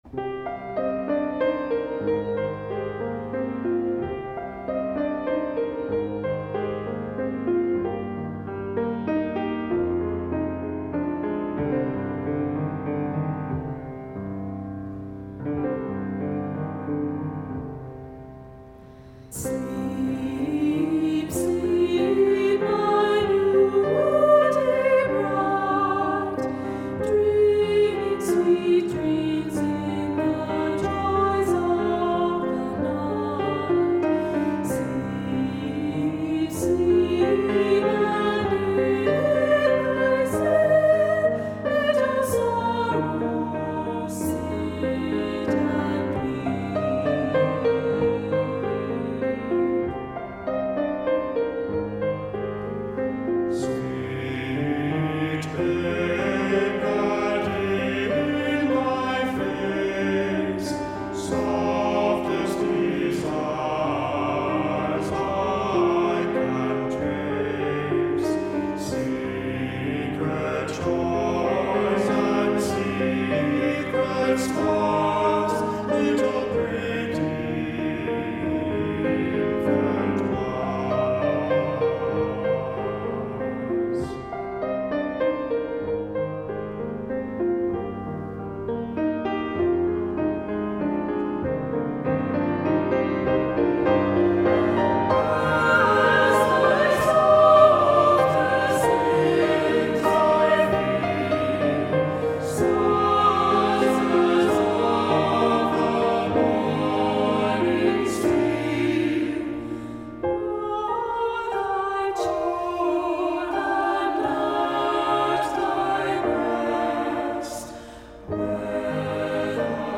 Voicing: "SATB"